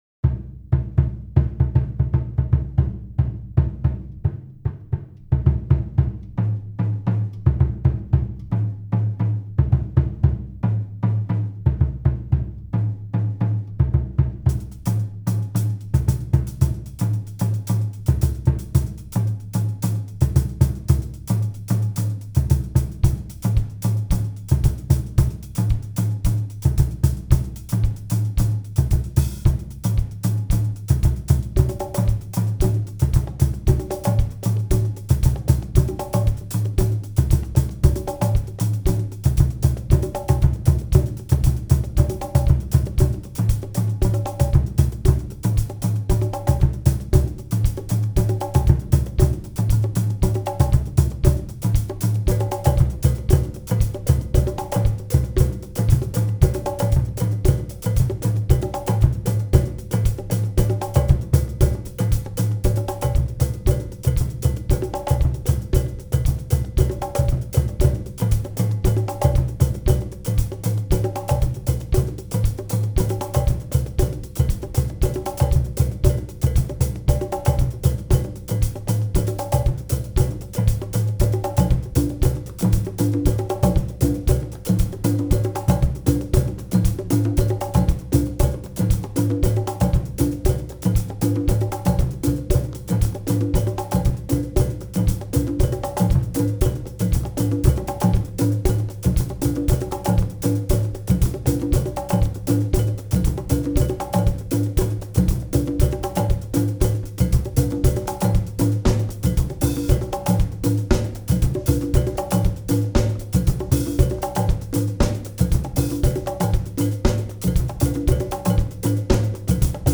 percussion bands